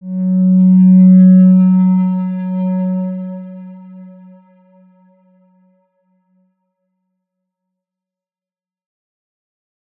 X_Windwistle-F#2-ff.wav